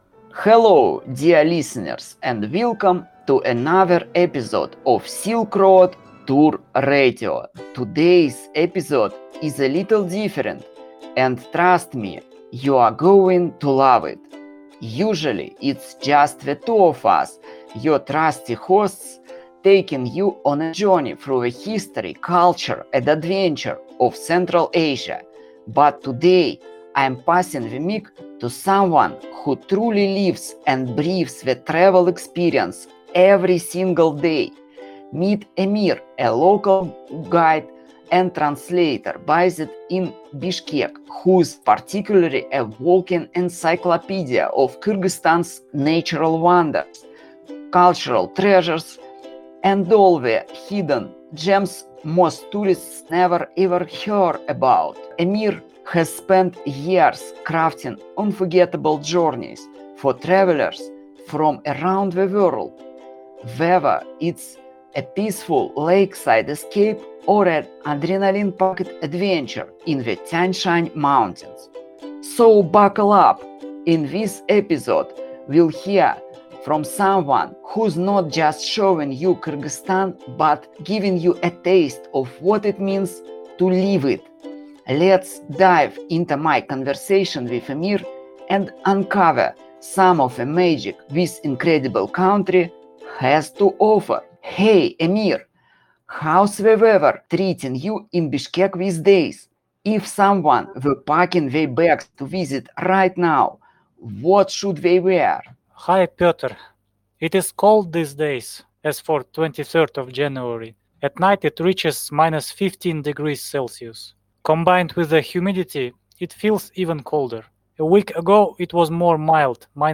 The Adventure Capital – Interview with local guide – Silk Road Tour Radio